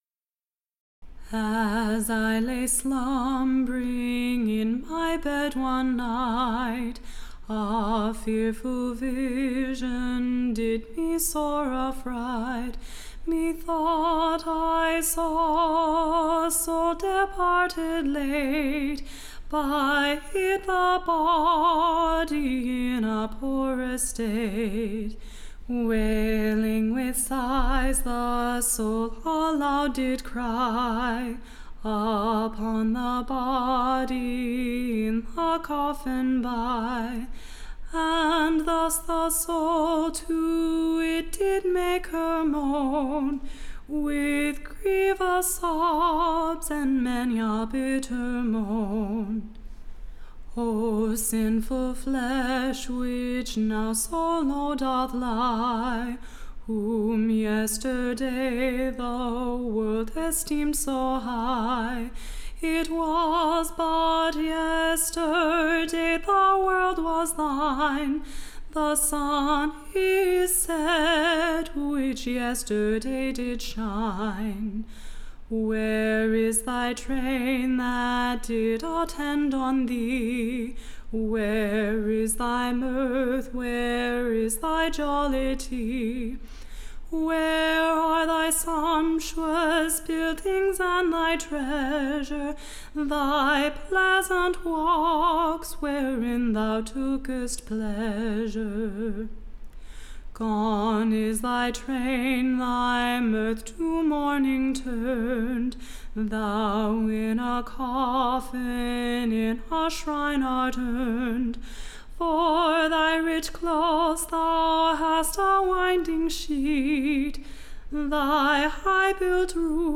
EBBA 30253 - UCSB English Broadside Ballad Archive
Tune Imprint To the Tune of, Fortune my Foe.